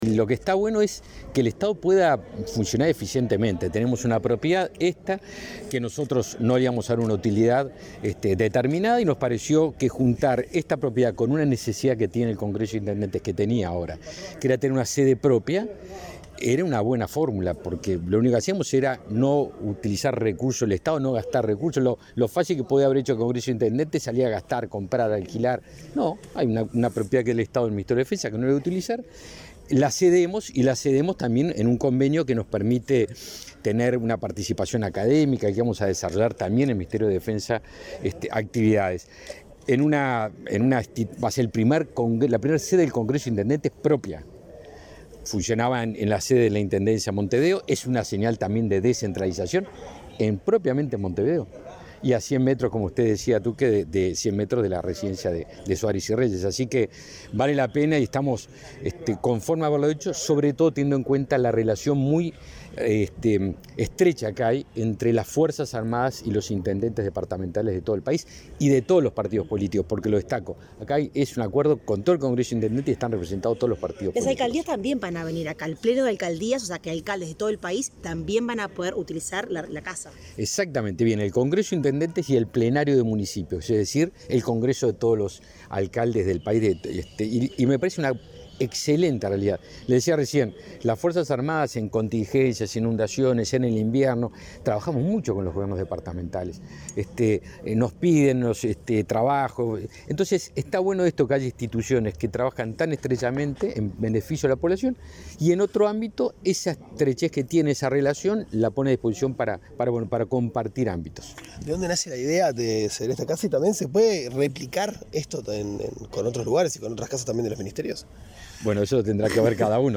Declaraciones del ministro de Defensa Nacional, Javier García